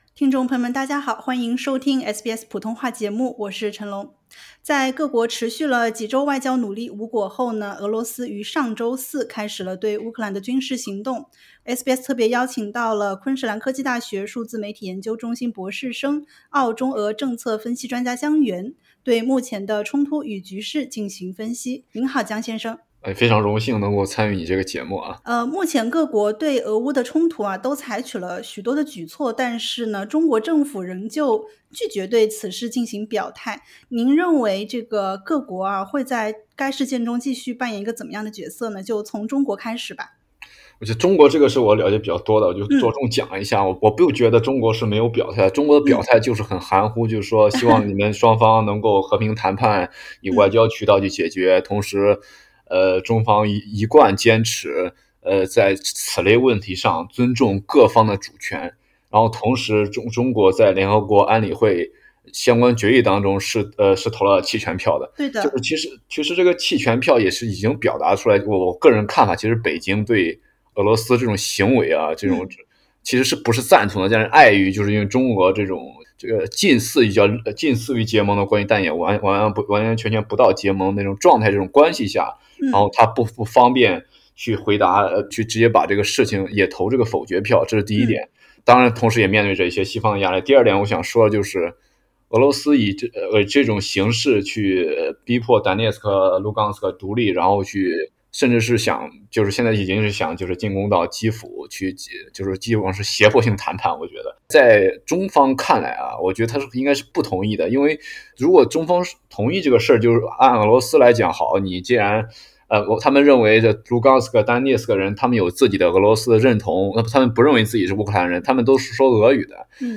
俄方觸犯國際規則以及中方核心利益的行動，讓中國併不會支持俄羅斯對烏克蘭的軍事行動，但也“不便”髮聲反對。（點擊圖片收聽完整寀訪）